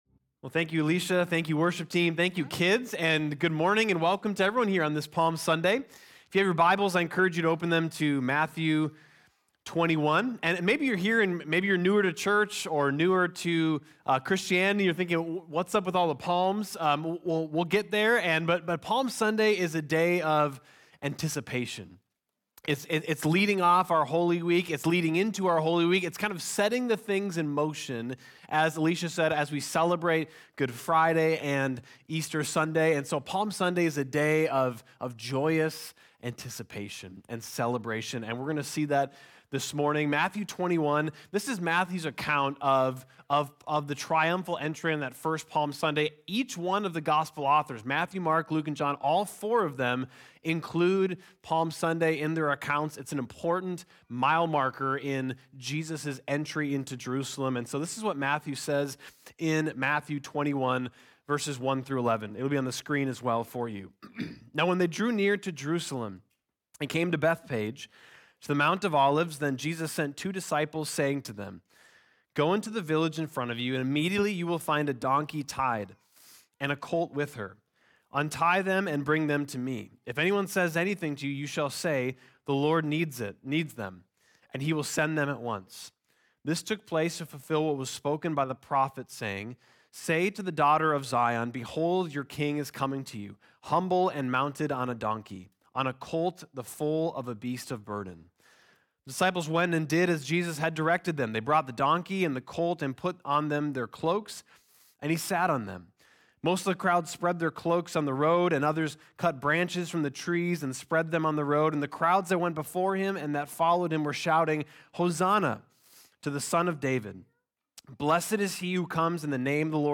Palm Sunday Service